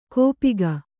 ♪ kōpiga